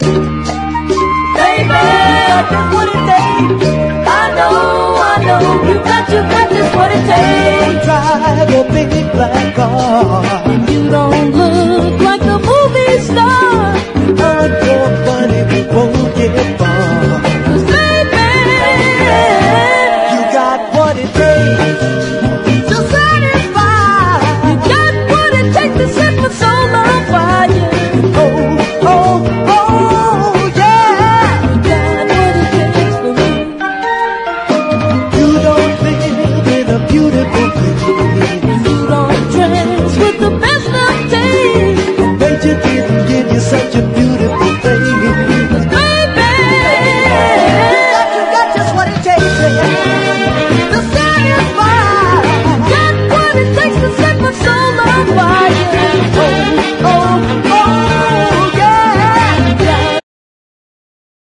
SOUTHERN SOUL